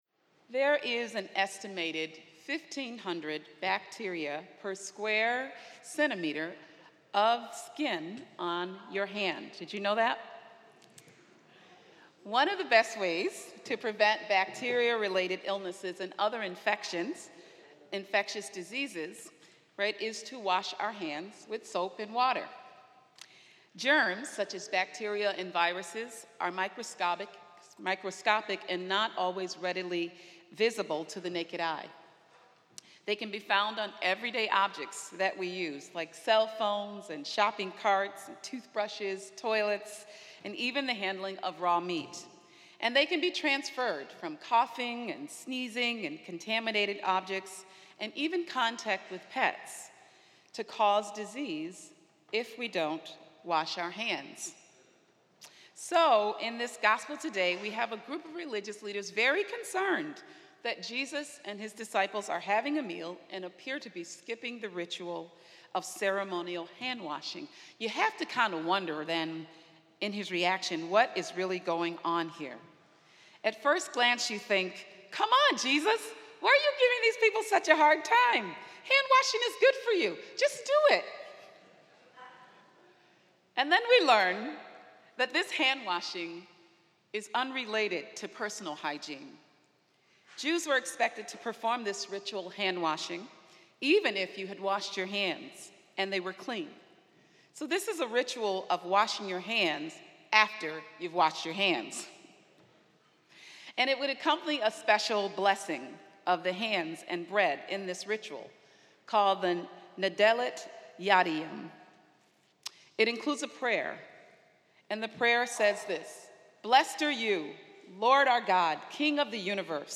This is a mass at Spiritus Christi Church in Rochester, NY.